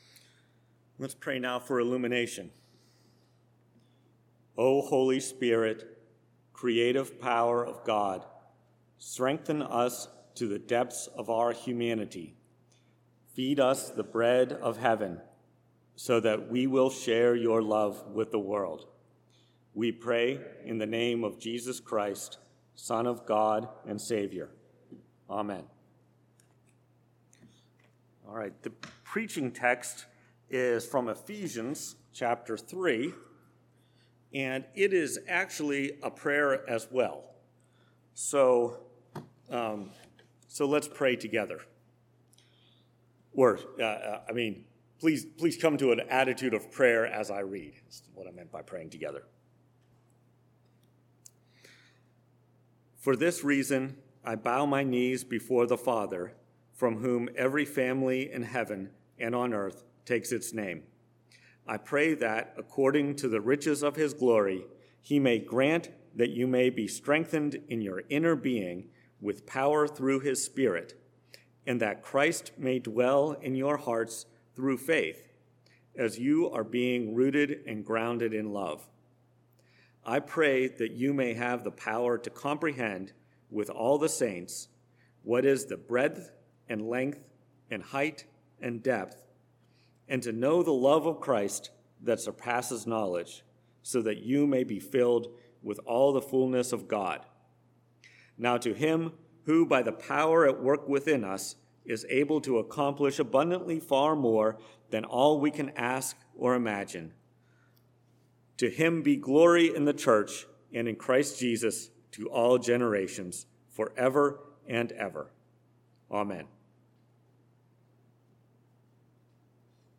Preached at First Presbyterian Church of Rolla. Based on Ephesians 3:14-21, John 6:1-21.